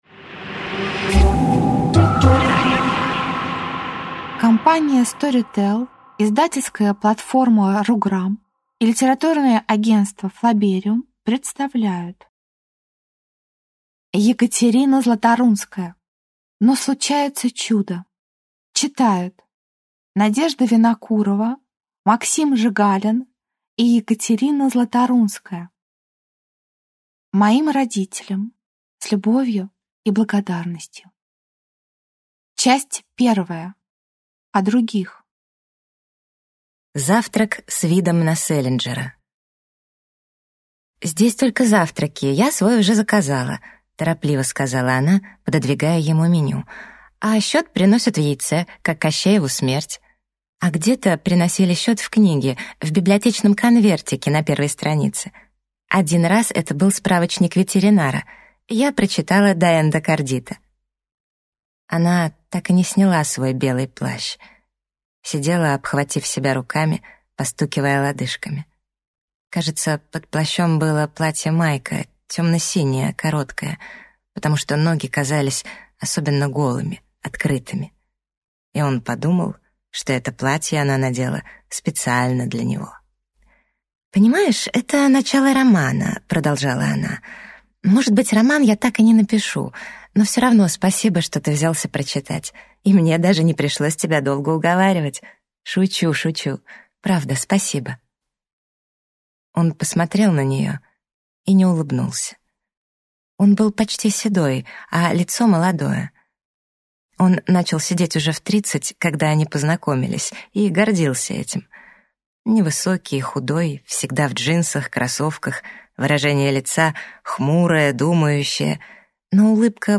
Аудиокнига Но случается чудо | Библиотека аудиокниг